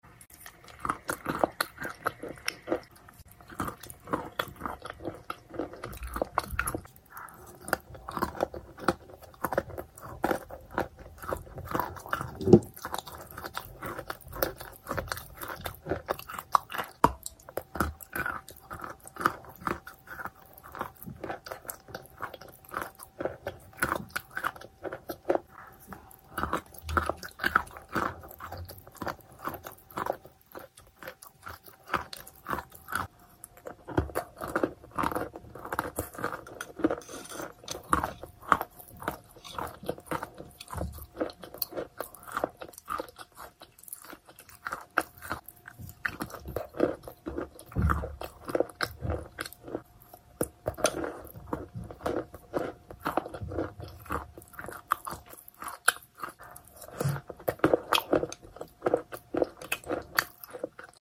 Sound Effects